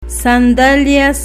Phonological Representation san'dalias